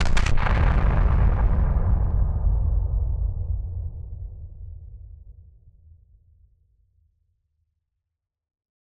BF_DrumBombB-06.wav